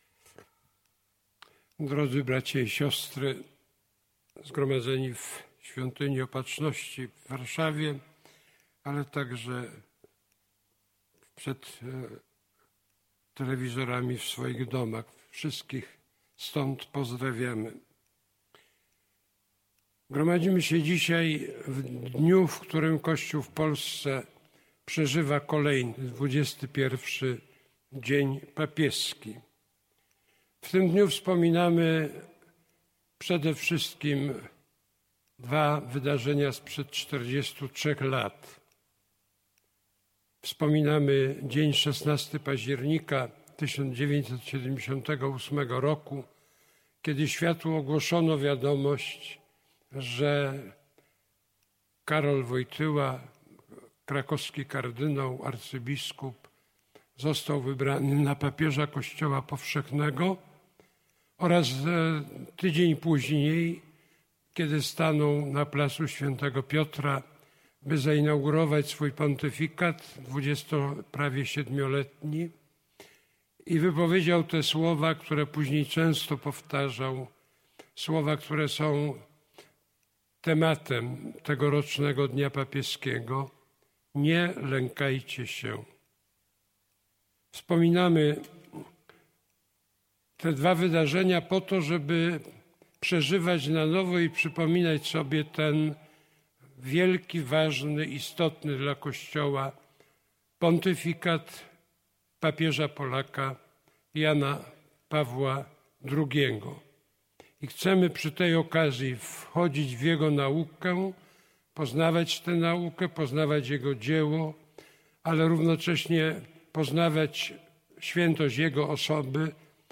Po raz kolejny obchody Dnia Papieskiego rozpoczęły się Eucharystią odprawianą w Świątyni Opatrzności Bożej.